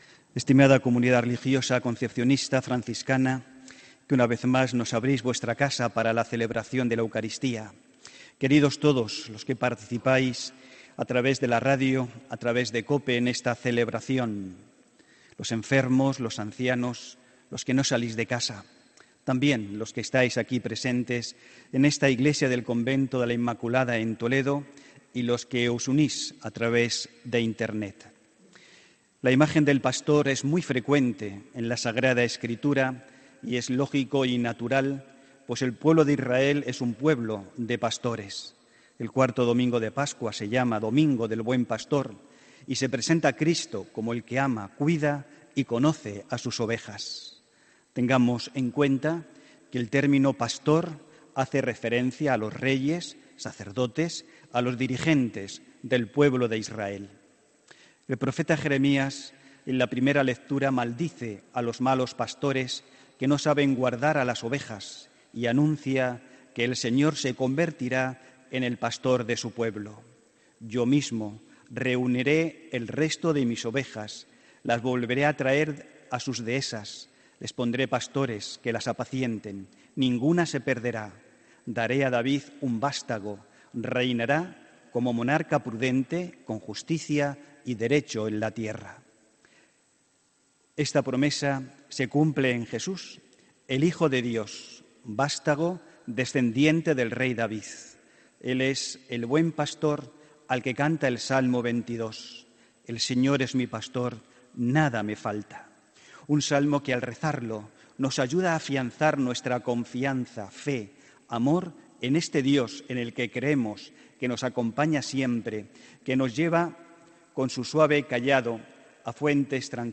HOMILÍA 22 JULIO 2018